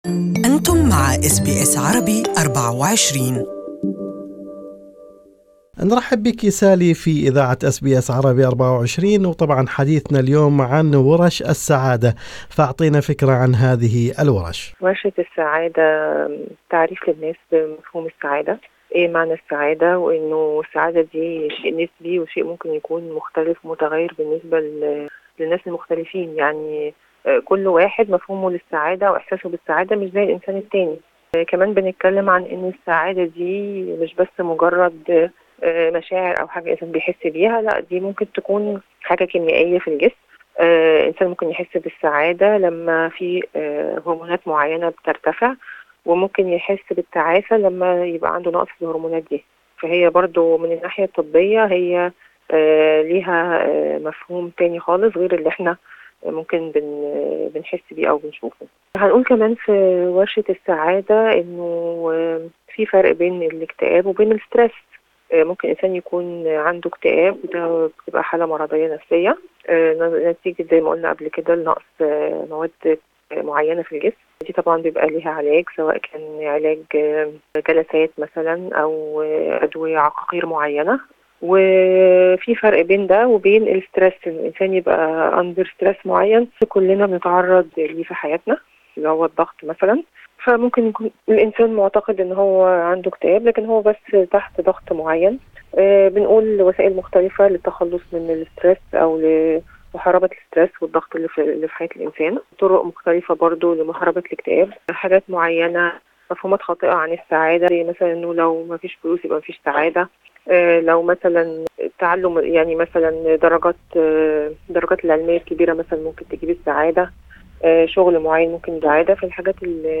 المزيد في المقابلة التالية